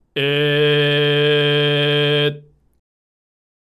※喉頭は巨人の状態でグーで口